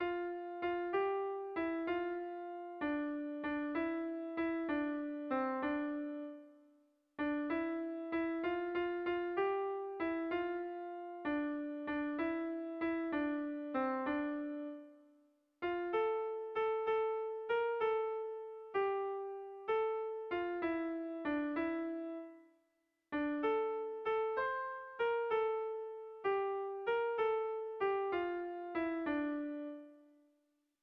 Alo mandozaina - Bertso melodies - BDB.
Kontakizunezkoa
AABD